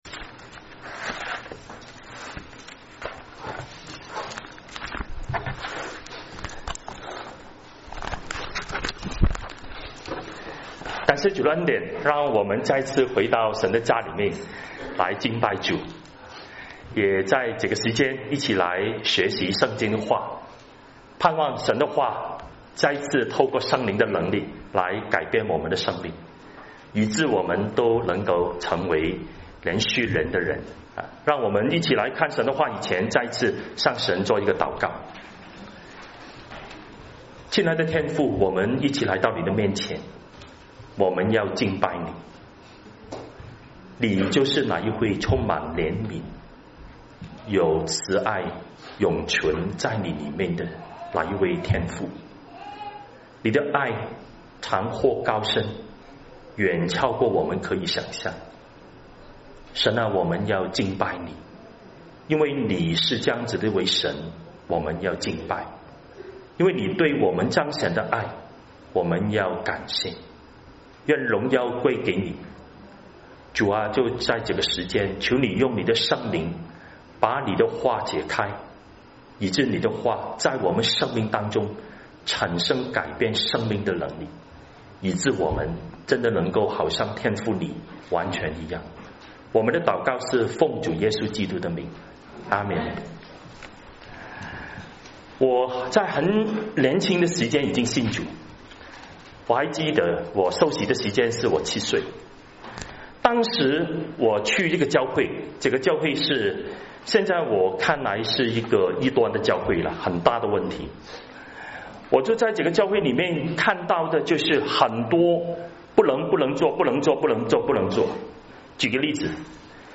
華埠粵語二堂